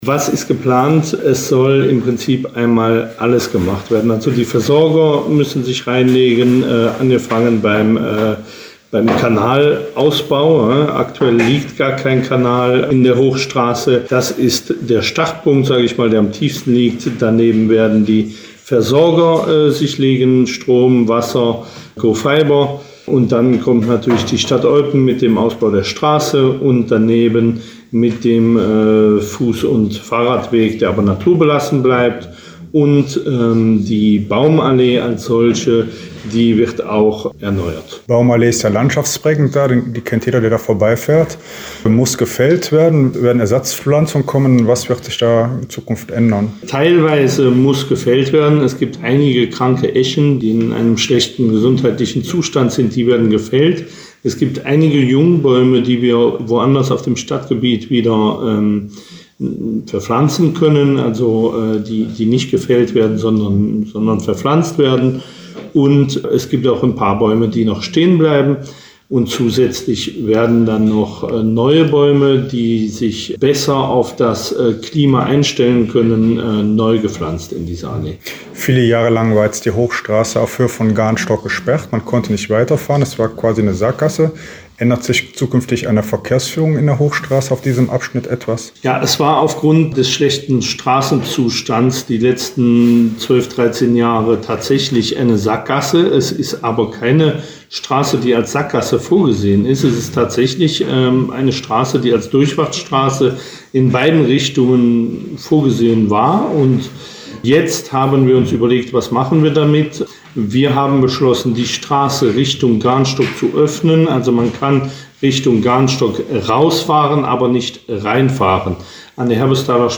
mit dem Eupener Bauschöffen Lucas Reul über die Details der Arbeiten gesprochen.